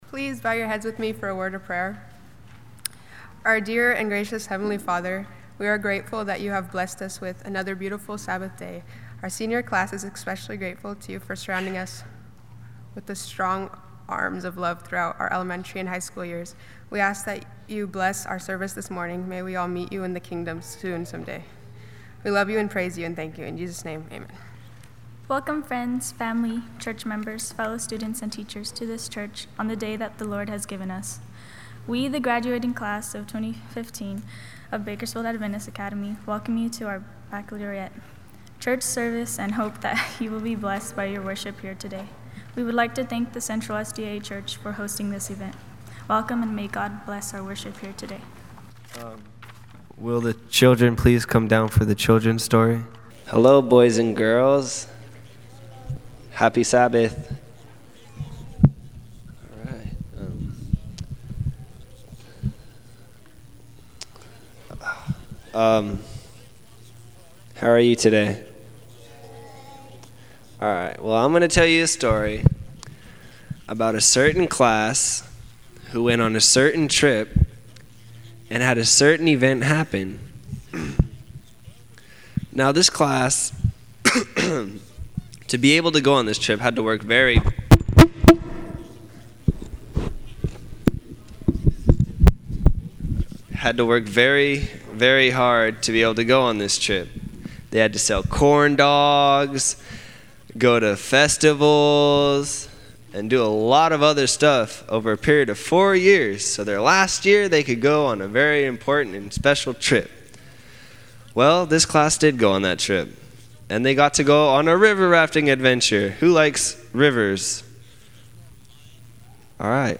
on 2015-06-05 - Sabbath Sermons